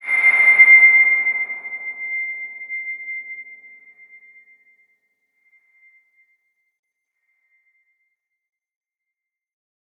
X_BasicBells-C5-pp.wav